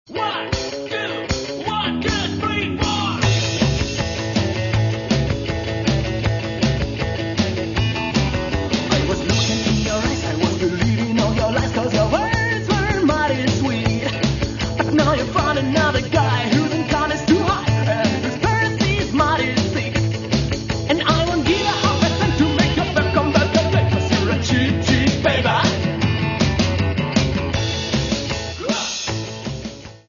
Catalogue -> Rock & Alternative -> Rockabilly